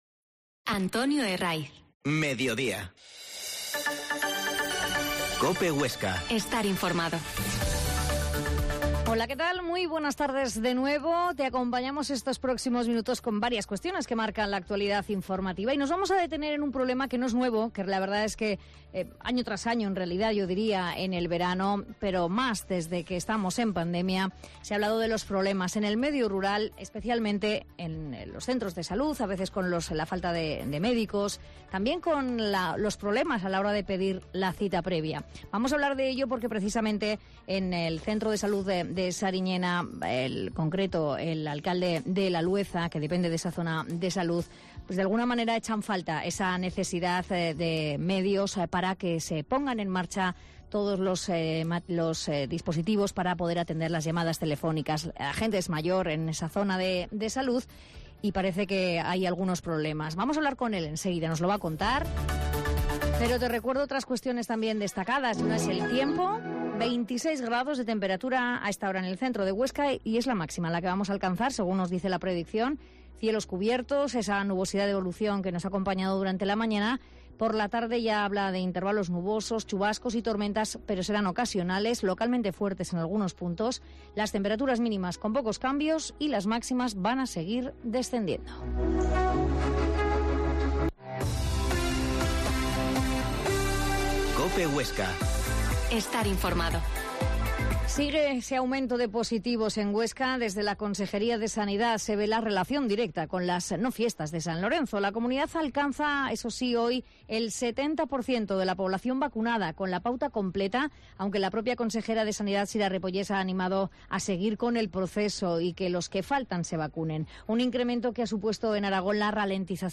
Mediodia en COPE Huesca 13.20h Entrevista al alcalde de Lalueza, Aramando Sanjuán